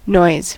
noise: Wikimedia Commons US English Pronunciations
En-us-noise.WAV